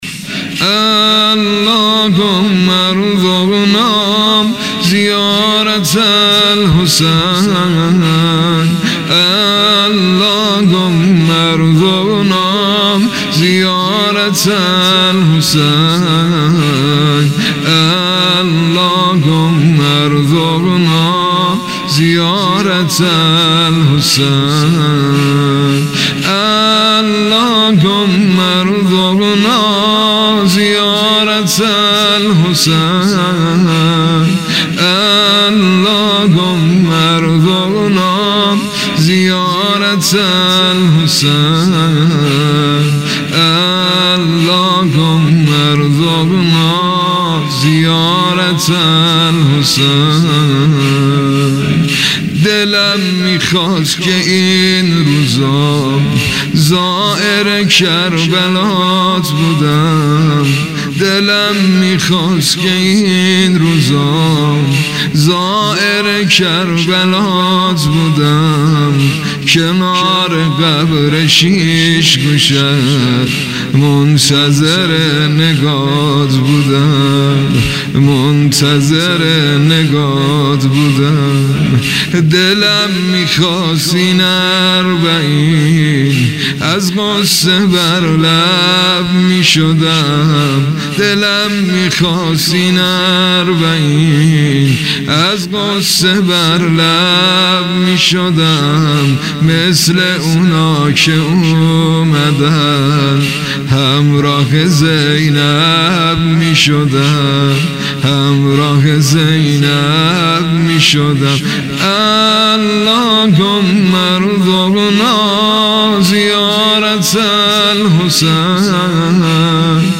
سبک ۱۱ ـ ذکر / واحد ـ کربلا
عنوان : شور